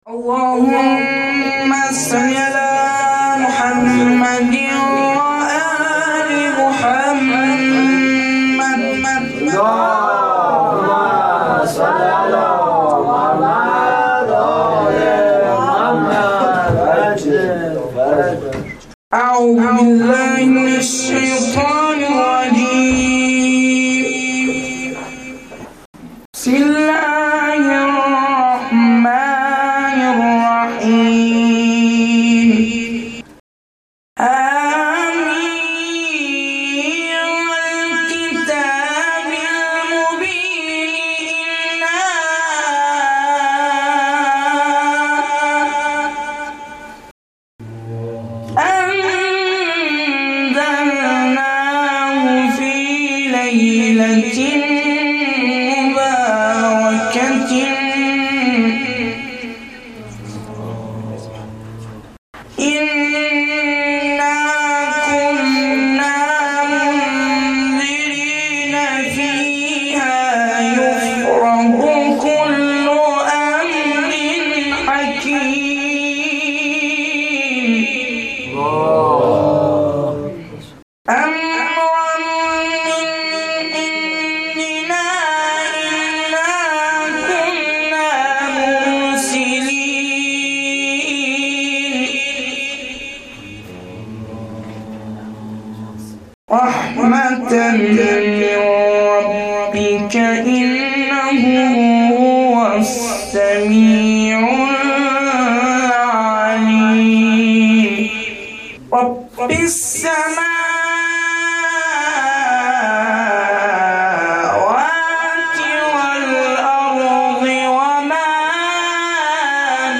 • قرائت شب چهارم فاطمیه 1393